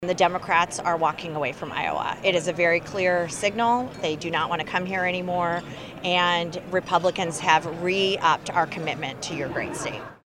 Statewide Iowa — The Iowa Republican Party’s fundraiser on August 10th showcased the GOP’s top of the ticket candidates this November and focused on the presidential campaign that will soon follow.